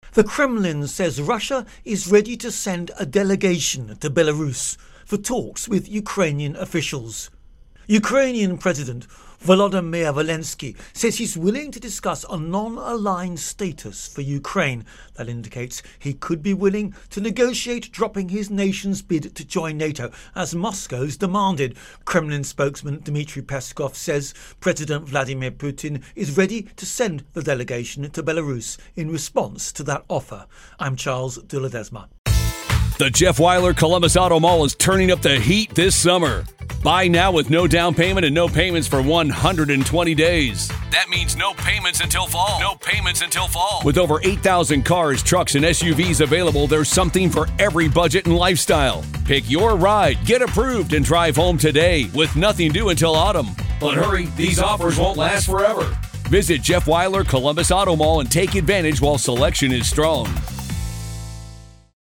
Ukraine-Invasion-Talks Intro and Voicer